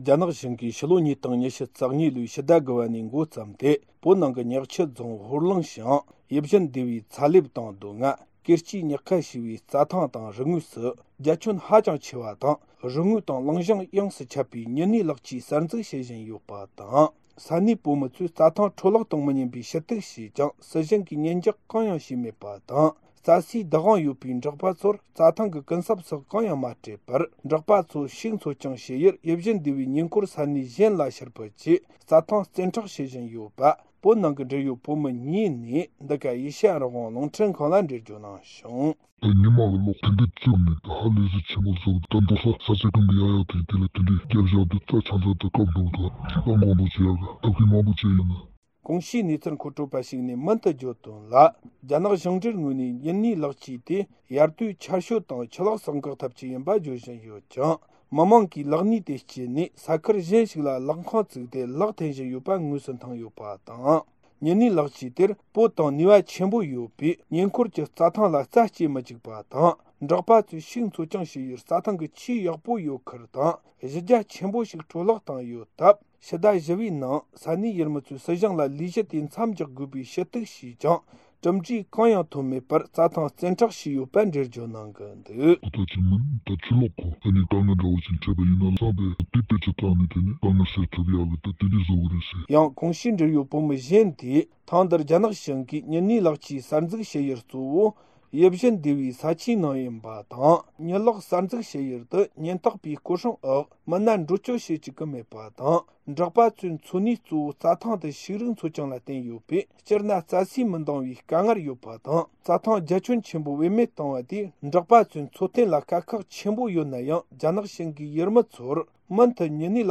སྒྲ་ལྡན་གསར་འགྱུར། སྒྲ་ཕབ་ལེན།
རྒྱ་ནག་གཞུང་གིས་ཕྱི་ལོ་ ༢༠༢༢ ལོའི་ཕྱི་ཟླ་ ༩ བ་ནས་མགོ་བརྩམས་ཏེ་བོད་ནང་གི་ཉག་ཆུ་རྫོང་ཧོར་ལུང་ཤང་གི་ཡིད་བཞིན་སྡེ་བའི་ཚ་ལེབ་དང་རྡོ་ལྔ། གེར་སྐྱའི་ཉག་ཁ་ཞེས་པའི་རྩྭ་ཐང་དང་རི་ངོས་སུ་རྒྱ་ཁྱོན་ཧ་ཅང་ཆེ་བའི་རི་ངོས་དང་ལུང་གཞུང་ཡོངས་སུ་ཁྱབ་པའི་ཉི་ནུས་གློག་ཆས་གསར་འཛུགས་བྱེད་བཞིན་ཡོད་པ་དང་། ས་གནས་ཡུལ་མི་ཚོས་རྩྭ་ཐང་འཕྲོ་བརླག་གཏོང་མི་ཉན་པའི་ཞུ་གཏུགས་བྱས་ཀྱང་སྲིད་གཞུང་གིས་ཉན་འཇོག་གང་ཡང་བྱས་མེད་པ་དང་། རྩྭ་སའི་བདག་དབང་ཡོད་པའི་འབྲོག་པ་ཚོར་རྩྭ་ཐང་གི་ཀུན་གསབ་སོགས་གང་ཡང་མ་སྤྲད་པར། འབྲོག་པ་ཚོ་ཕྱུགས་འཚོ་སྐྱོང་བྱེད་ཡུལ་ཡིད་བཞིན་སྡེ་བའི་ཉེ་འཁོར་ས་གནས་གཞན་ལ་ཕྱིར་ཕུད་ཀྱིས་རྩྭ་ཐང་བཙན་འཕྲོག་བྱེད་བཞིན་ཡོད་པ། བོད་ནང་གི་འབྲེལ་ཡོད་བོད་མི་གཉིས་ནས་འདི་ག་ཨེ་ཤི་ཡ་རང་དབང་རླུང་འཕྲིན་ཁང་ལ་འགྲེལ་བརྗོད་གནང་བྱུང་།